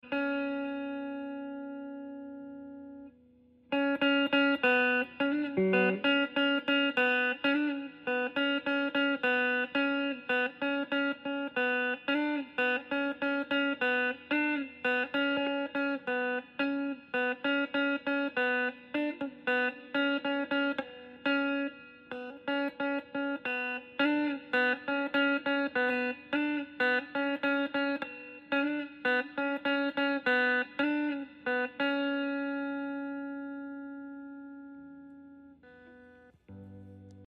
on guitar